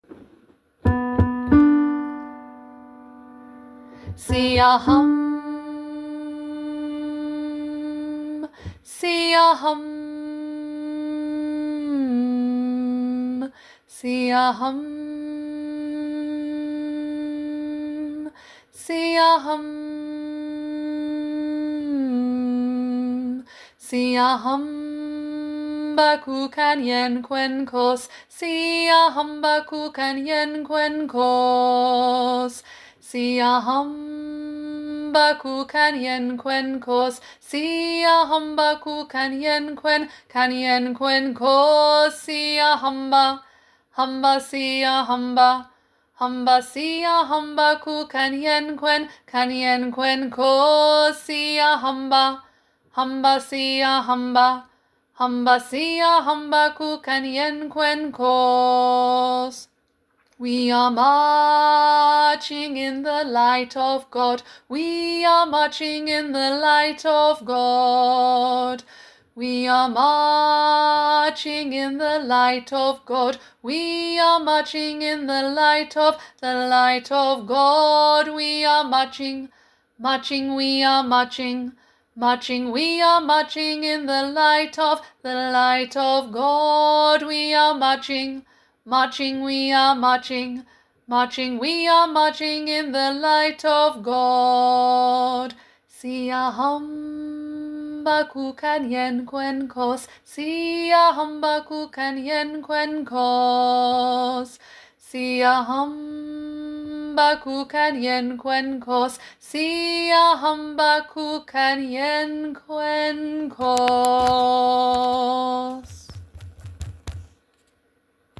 Siyahamba- Tenor